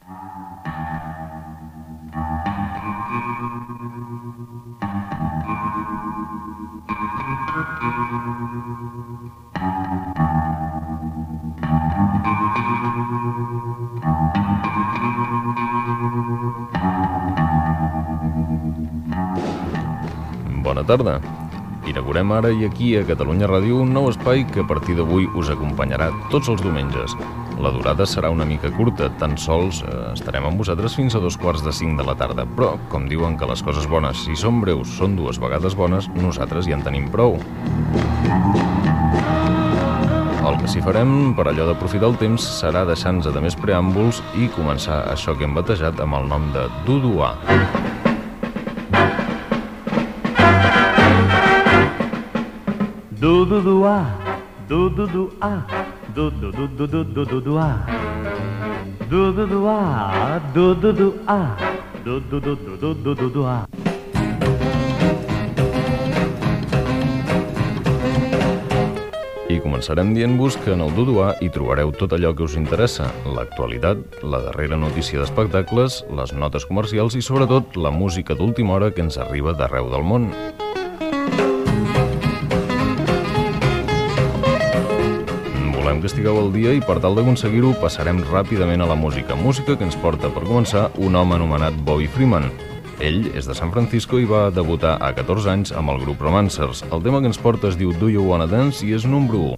Presentació inicial del programa, sintonia, continguts i tema musical
Musical